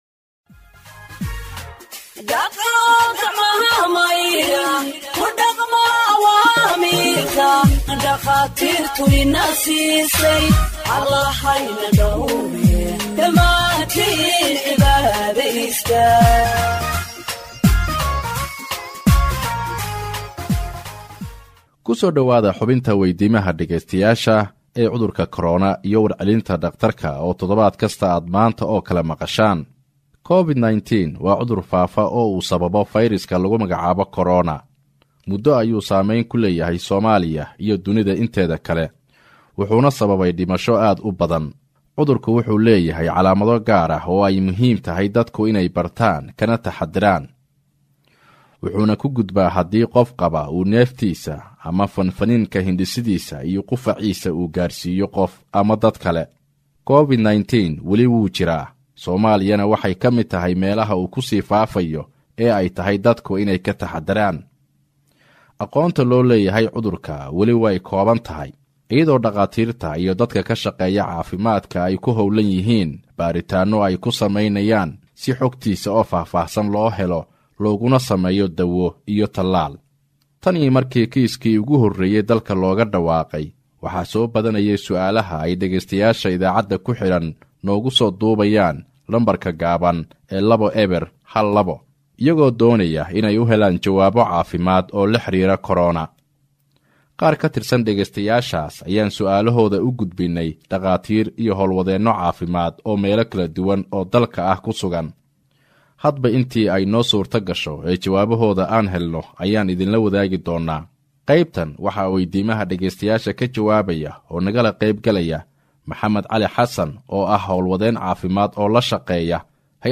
HEALTH EXPERT ANSWERS LISTENERS’ QUESTIONS ON COVID 19 (58)
Radio Ergo provides Somali humanitarian news gathered from its correspondents across the country for radio broadcast and website publication.